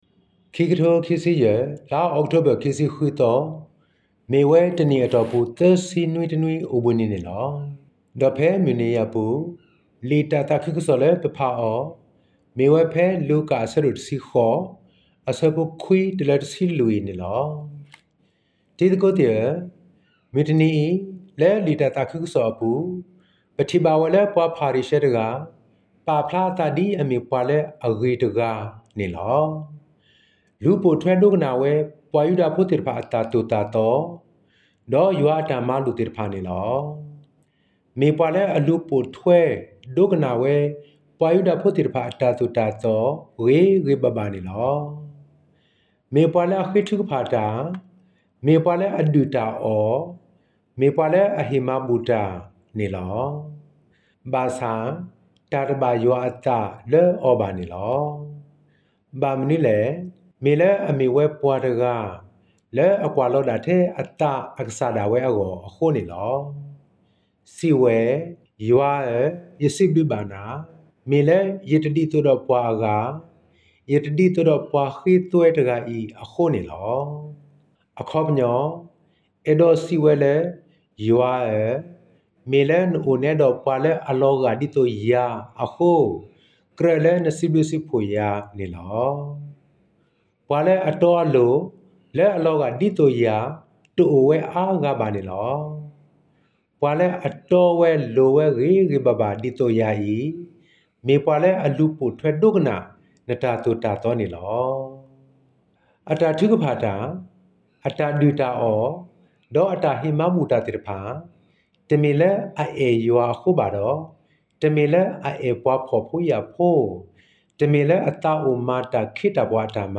G30th-Sunday-Reflection-in-Karen-Oct-26.mp3